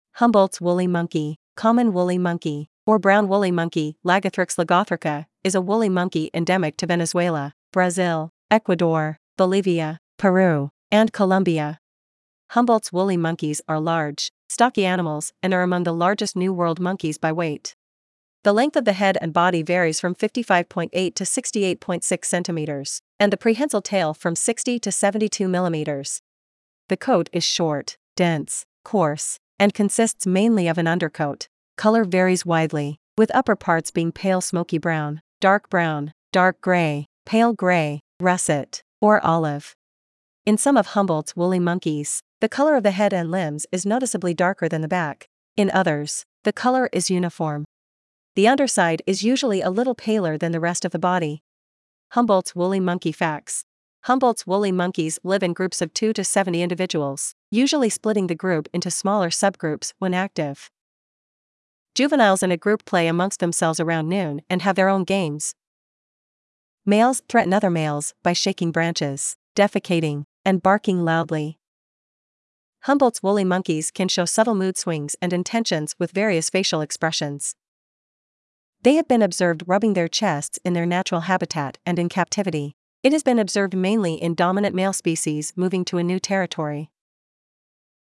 Humboldt’s Woolly Monkey
Humboldts-woolly-monkey.mp3